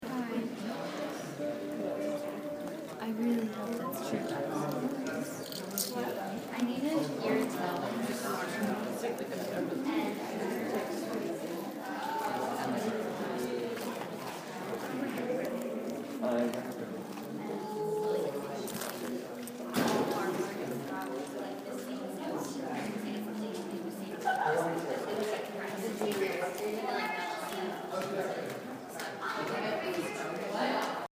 Field Recording #8
Sounds Heard: People talking, someone singing, keys jingling, doors closing, candy wappers crinkeling, people laughing